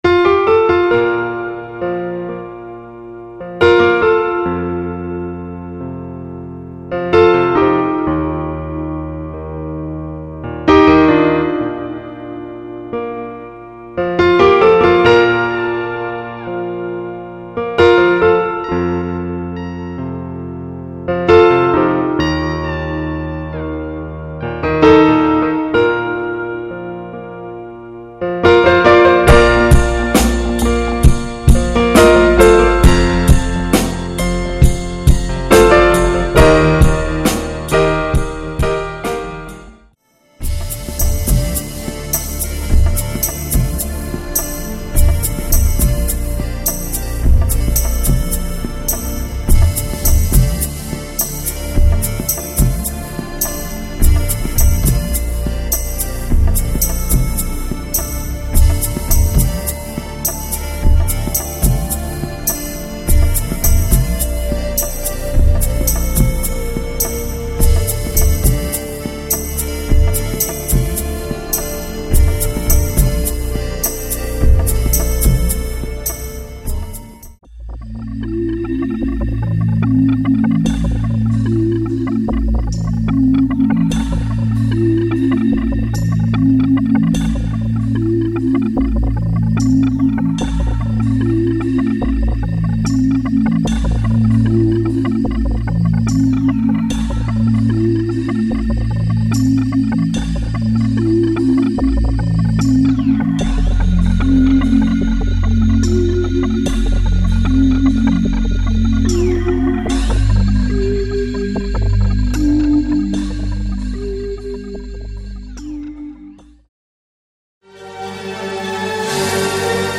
Mit Anspielern aller Titel
Sanftes Piano Thema mit einer Art Slide Guitar im Background
Indisch angehauchtes Thema mit Tablas und female Vocals
Orchestral anmutendes Werk mit Streichern - großes Kino
Rock Pop Thema im 80ties Style mit Gitarren Synthie
Eingängiges Thema mit Einwürfen einer Dampfpfeife
Synthie Thema mit viel Raum und einem sehr zarten Charakter
E-Piano Thema, mit Latinogitarre, E-Bass und Vocal Sample
Harmonisches Slow Rock Thema mit E-Piano, Orgel und Bass